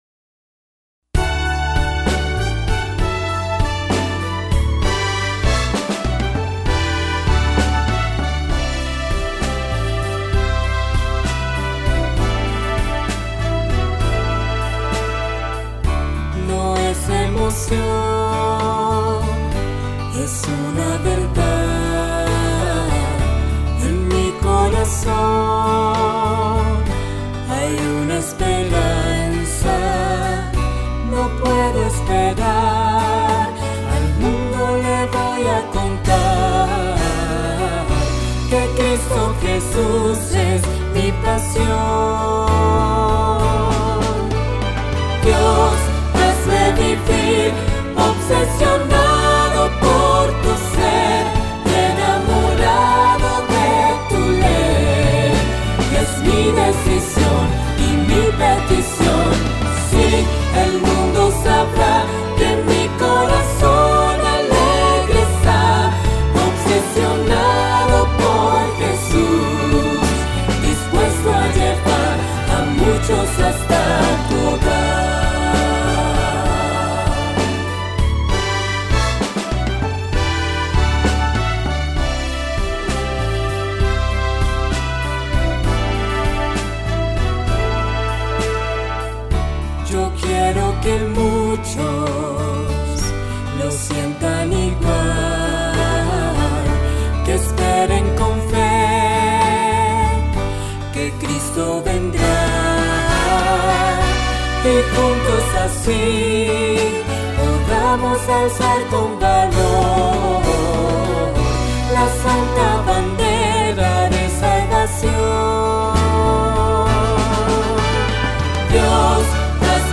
on 2008-04-30 - Música cristiana